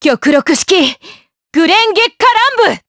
Seria-Vox_Skill7_jp.wav